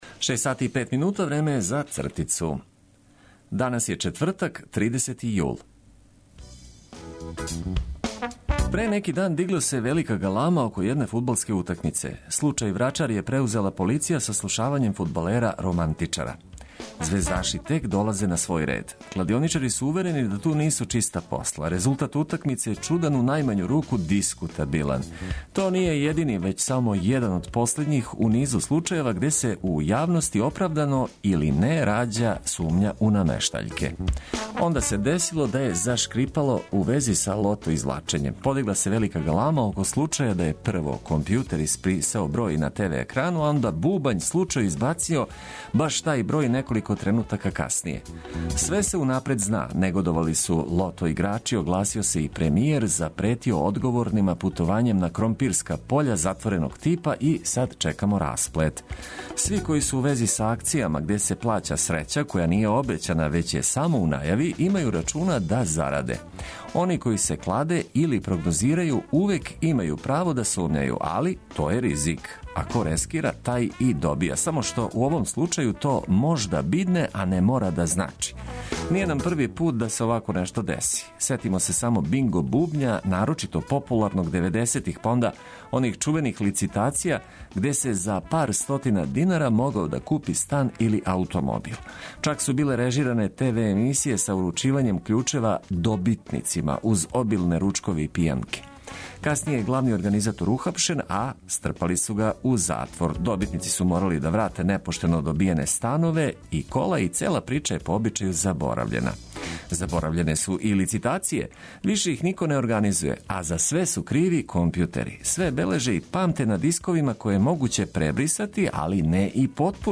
Корисне информације уз обиље добре музике, то је одлика заједничког започињања новог дана, јединог могућег који претходи петку.